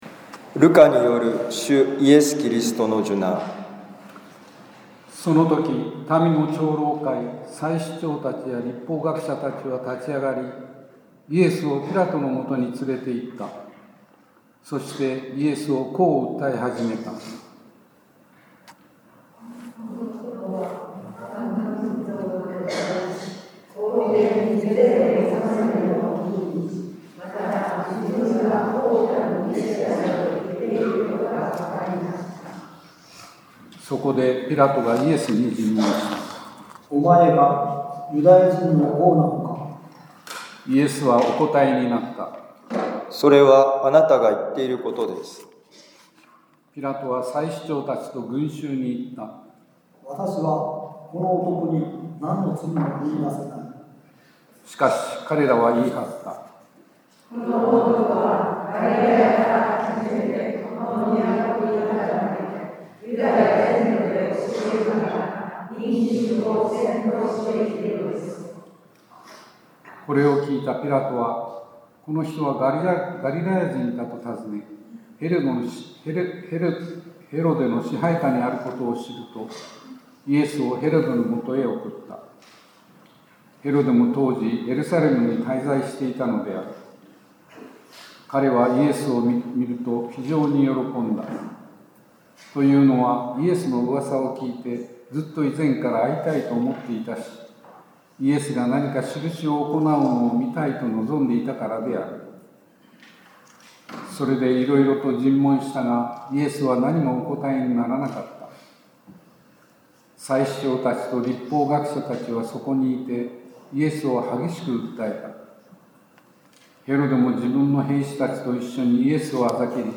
ルカ福音書23章1-49節「終わりのときははじまりのとき」2025年4月13日受難の主日ミサ カトリック長府教会